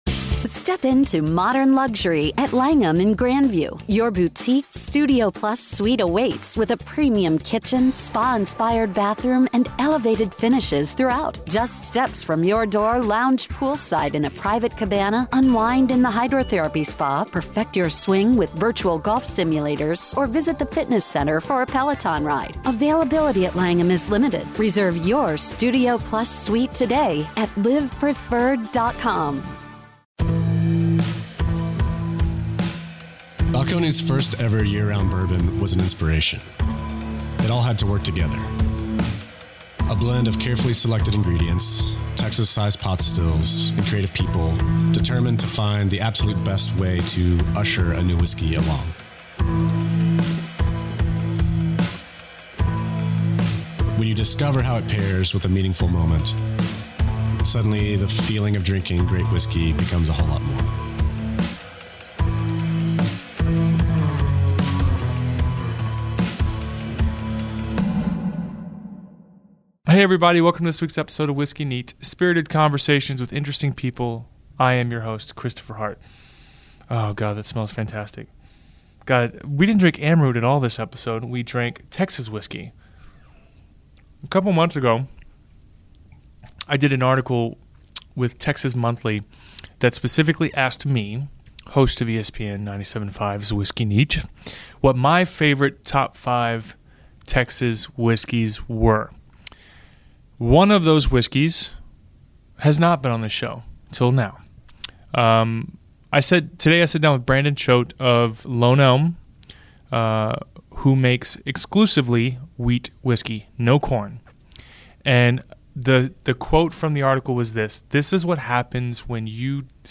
Whiskey Neat is a Radio show on iTunes and ESPN 97.5 FM in Houston and is brought to you every week by the following sponsors.